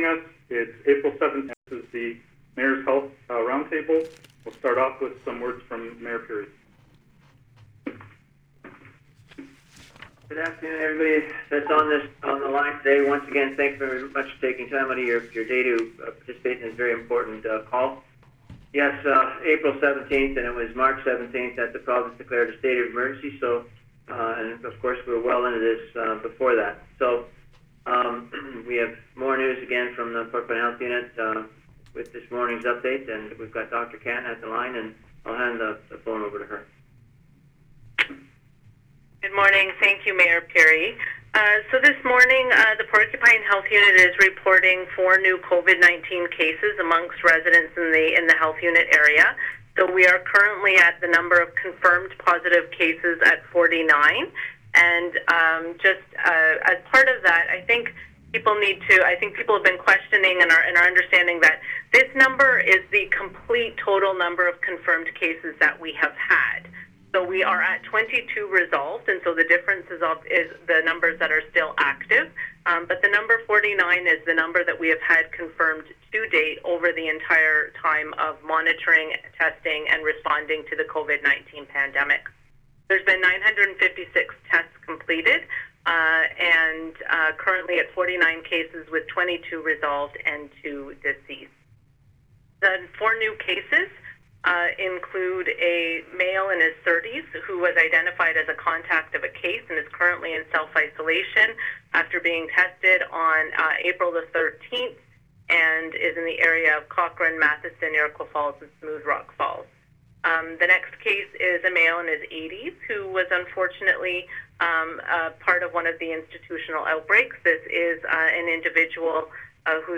Meanwhile, here is the raw audio.